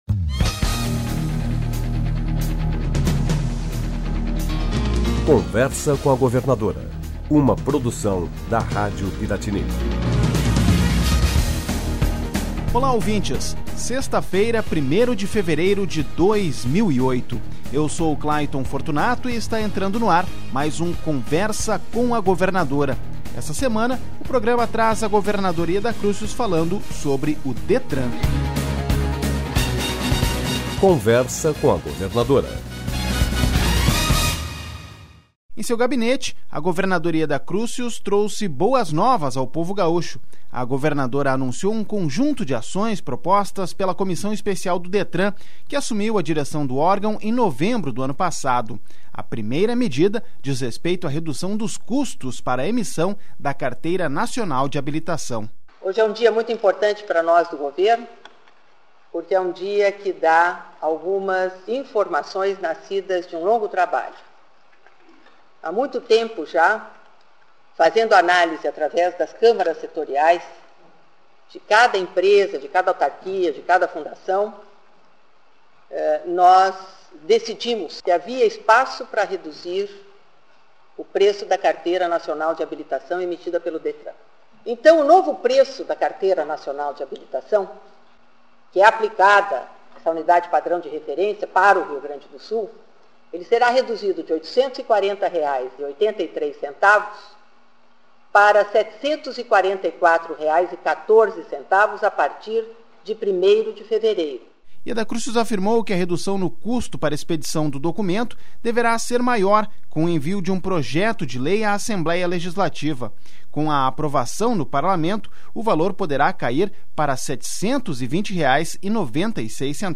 Em seu programa semanal de rádio, a governadora anunciou a redução nos custos para expedição da Carteira Nacional de Habilitação.